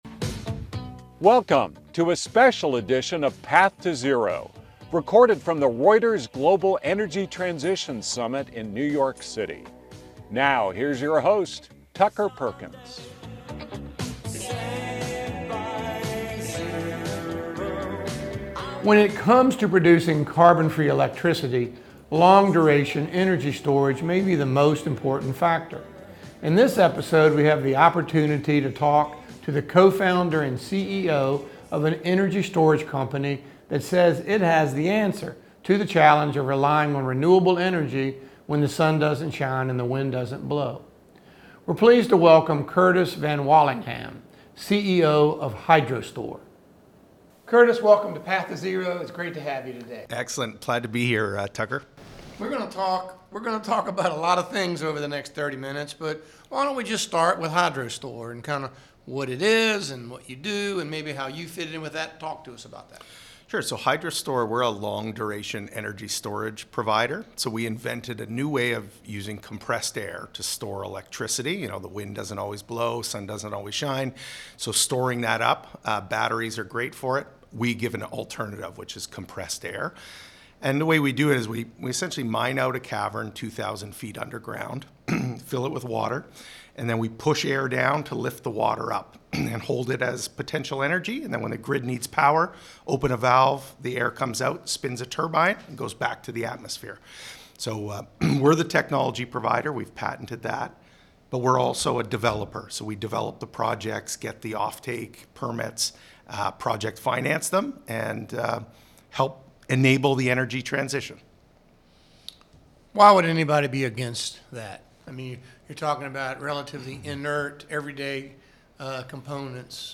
recorded at the Reuters Global Energy Transition Summit in New York City